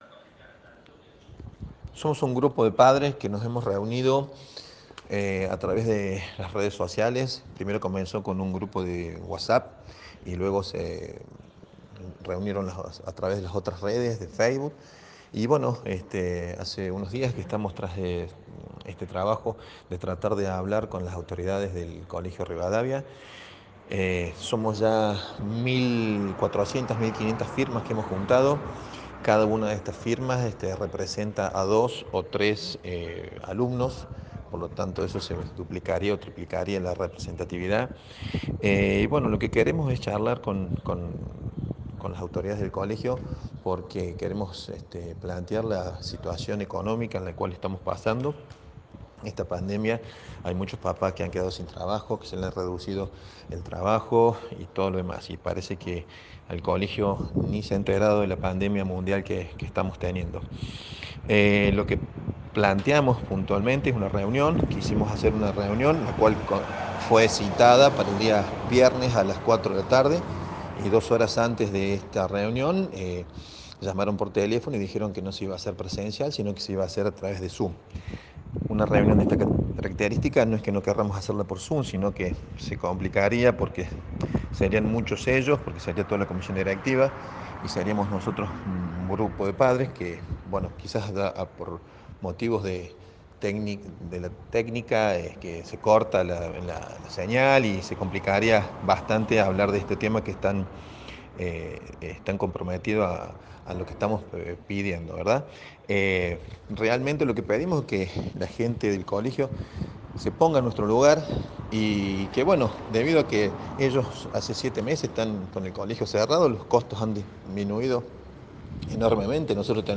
En diálogo con Cadena 3 Villa María, pidió que las autoridades reciban al grupo de padres para tratar el tema cuotas y matricula 2021.